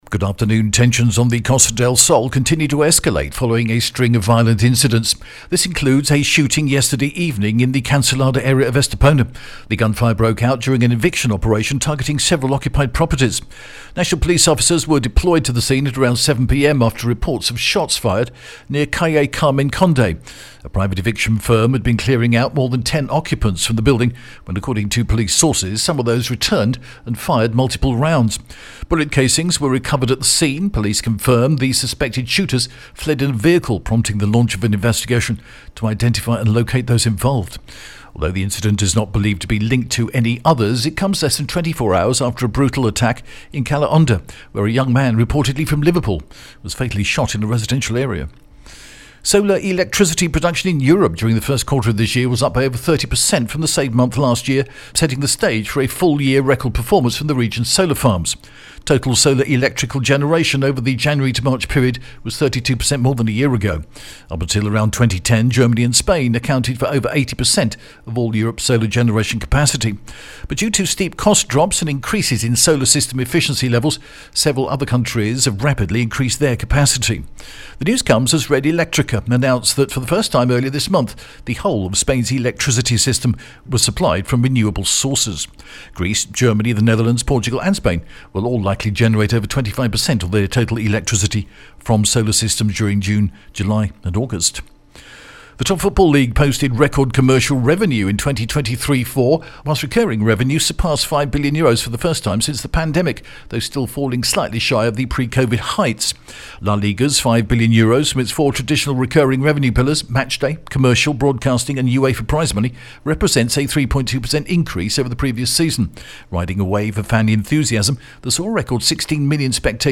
The latest Spanish news headlines in English: April 23rd 2025